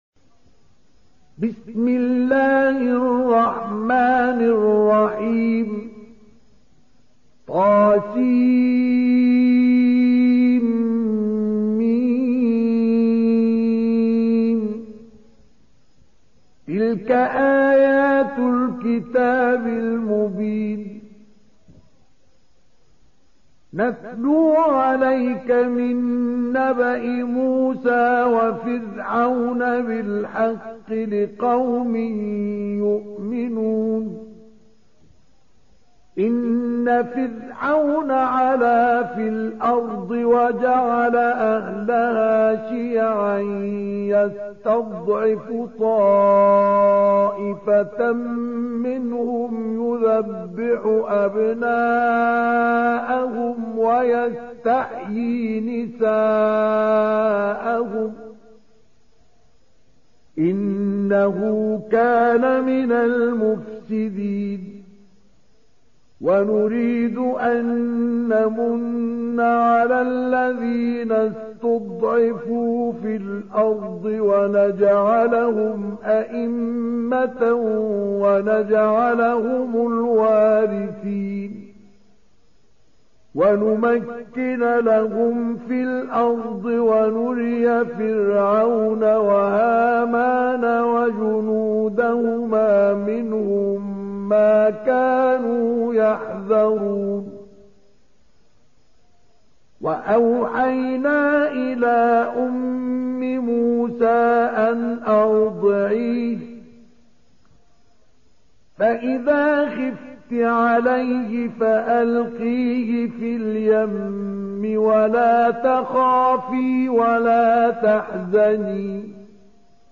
28. Surah Al-Qasas سورة القصص Audio Quran Tarteel Recitation
Surah Repeating تكرار السورة Download Surah حمّل السورة Reciting Murattalah Audio for 28. Surah Al-Qasas سورة القصص N.B *Surah Includes Al-Basmalah Reciters Sequents تتابع التلاوات Reciters Repeats تكرار التلاوات